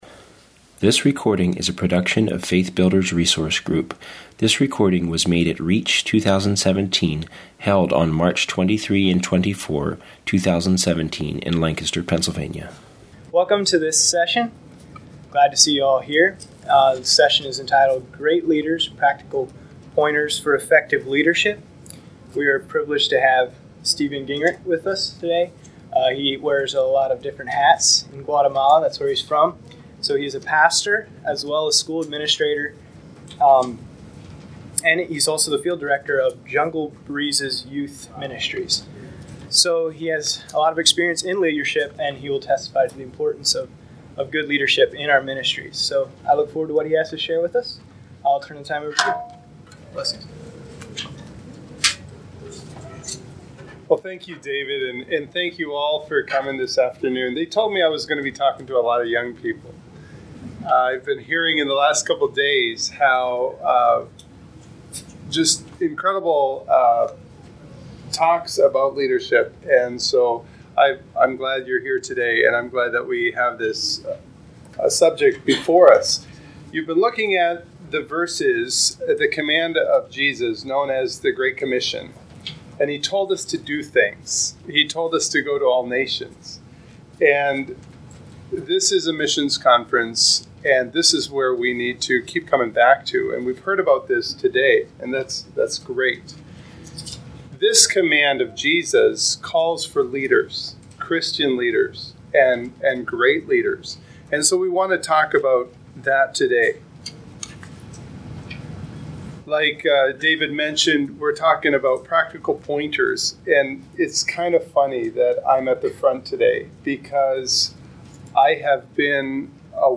Home » Lectures » Great Leaders